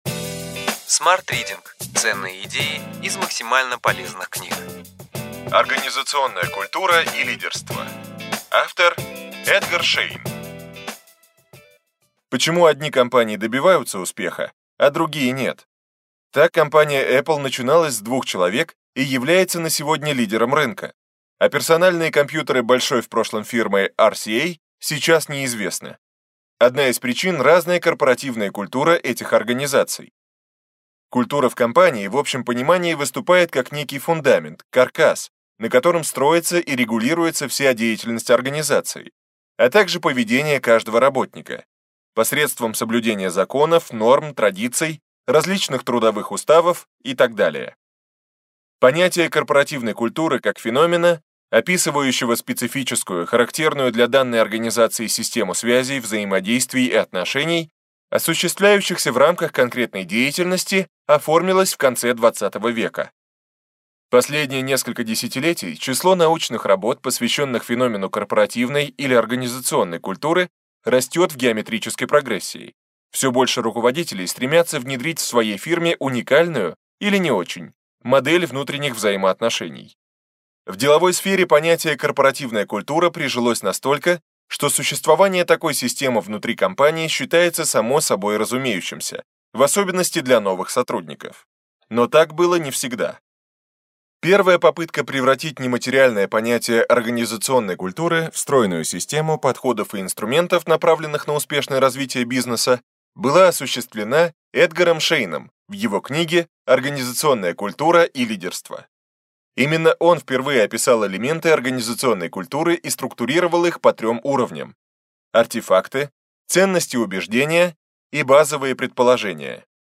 Аудиокнига Ключевые идеи книги: Организационная культура и лидерство.